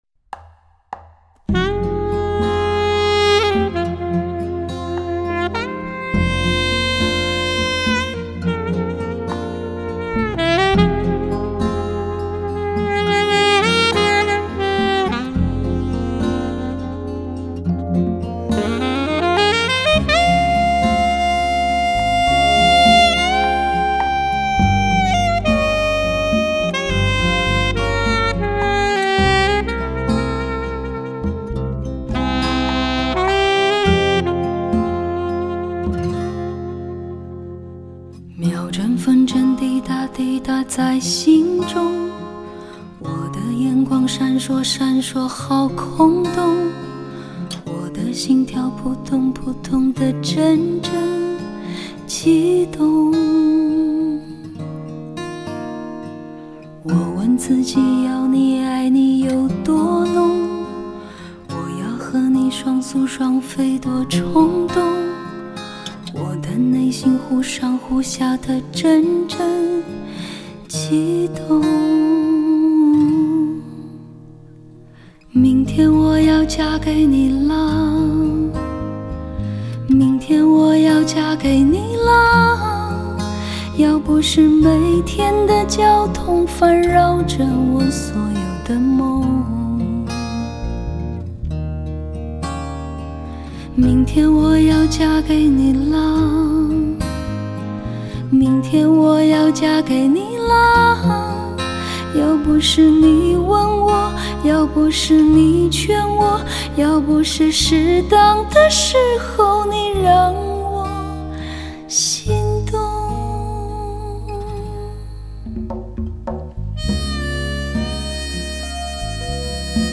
深厚的演唱功底 充满情感的声音媚力 极度磁性的嗓音特质